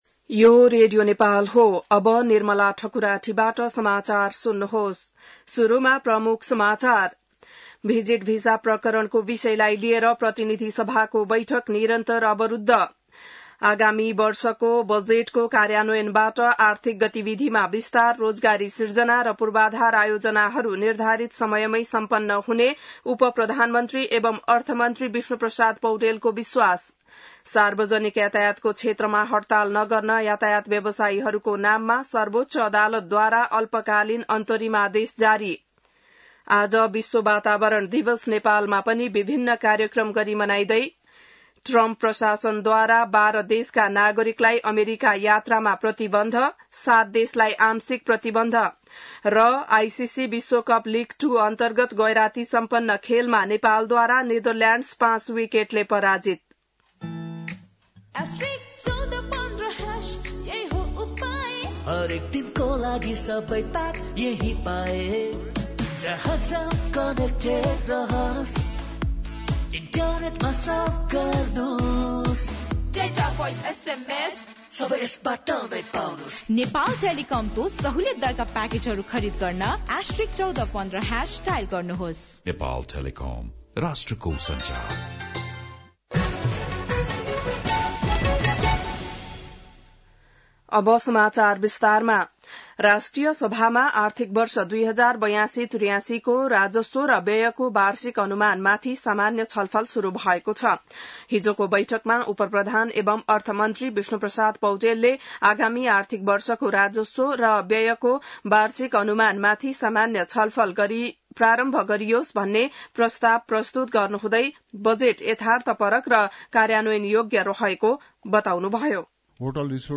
An online outlet of Nepal's national radio broadcaster
बिहान ७ बजेको नेपाली समाचार : २२ जेठ , २०८२